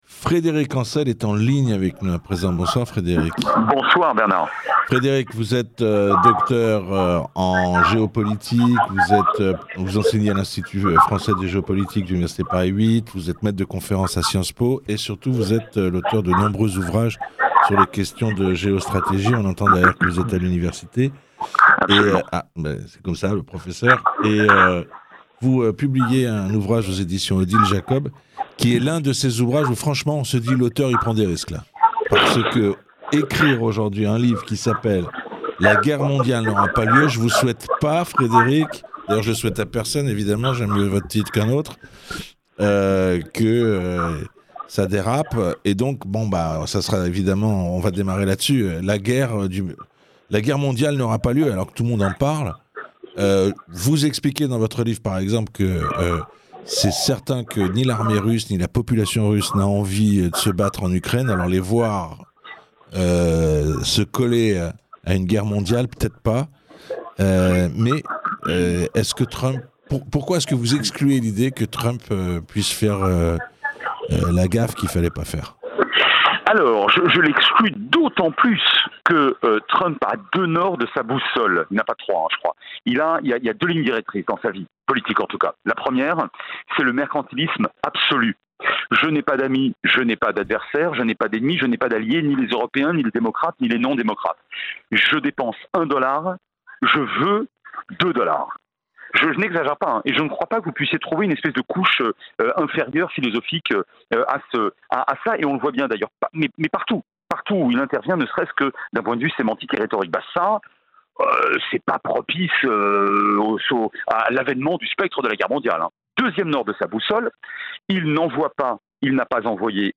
Universitaire, docteur en Géopolitique de l’Université Paris 8, professeur de Relations internationales (PSB) et maître de conférences à Sciences-Po Paris, Frédéric est un expert. Pour lui, il n'y aura pas de guerre mondiale.